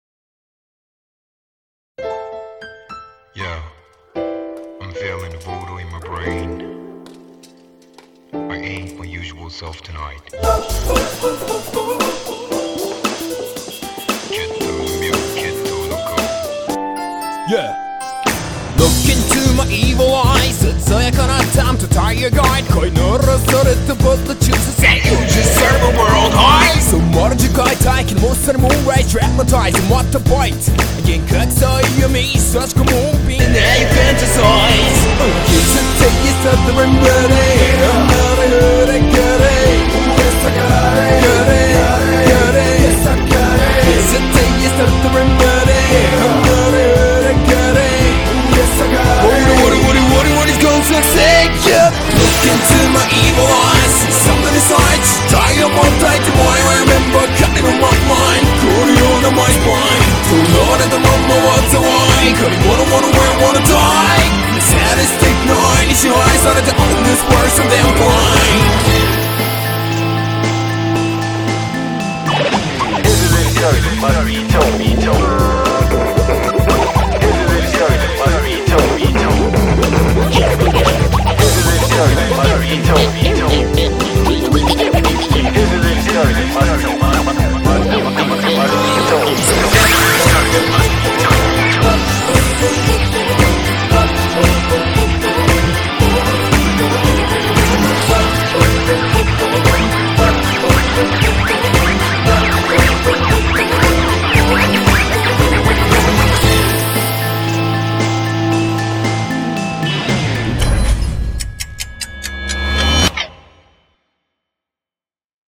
BPM115
Audio QualityPerfect (Low Quality)